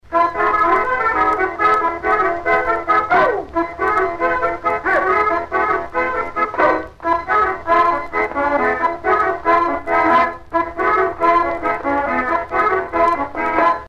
Branle - 9
Marais Breton Vendéen
danse : branle : courante, maraîchine
Pièce musicale éditée